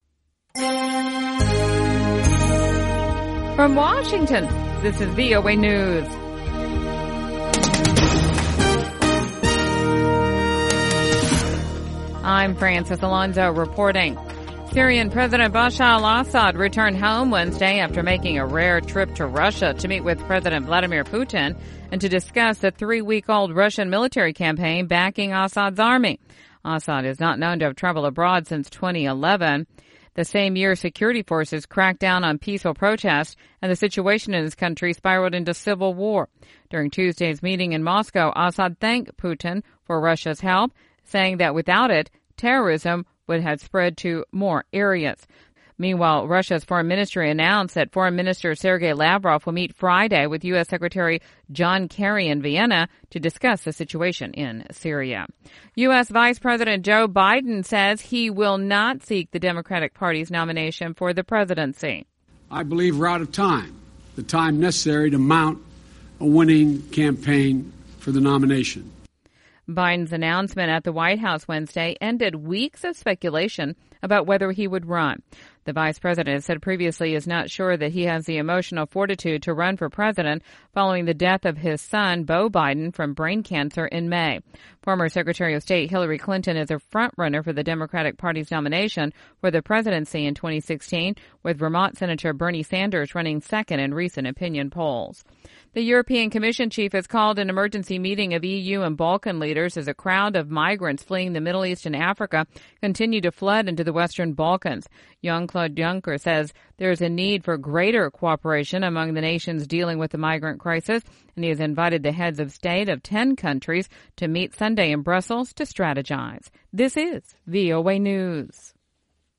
VOA English Newscast 1700 UTC October 21, 2015